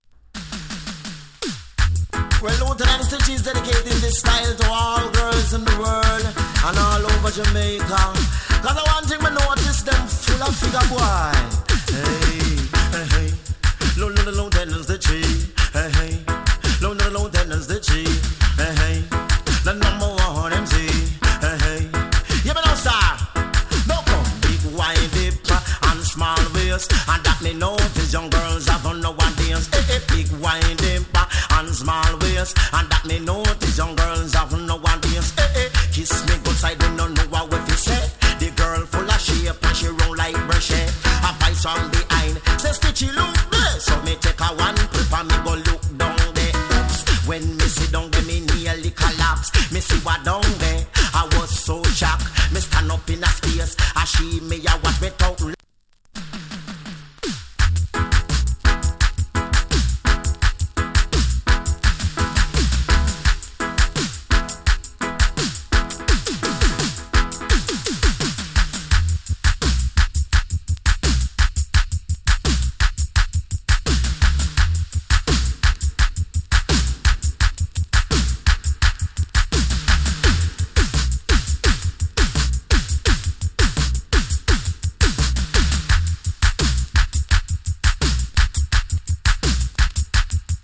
A：VG+ / B：VG+ ＊JUKE BOX のアトわずかに有り。ジリノイズ少し有り。
87年 NICE DEE-JAY !